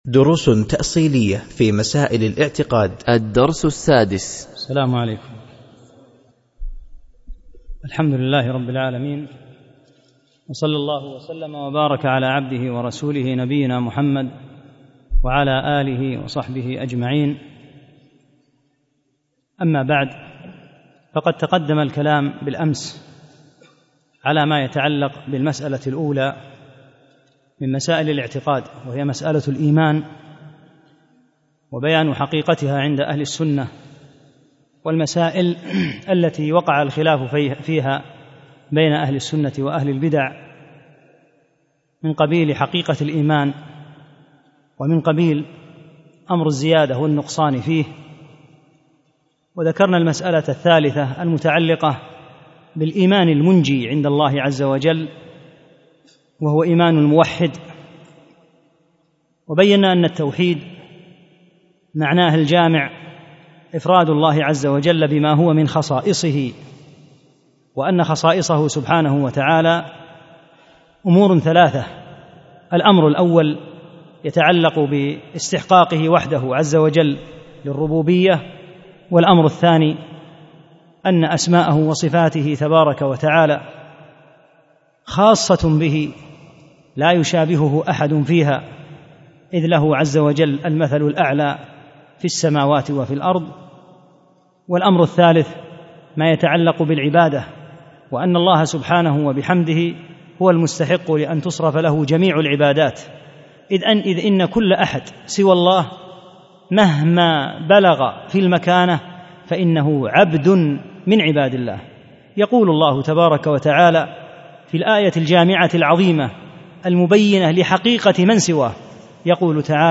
6 - الدرس السادس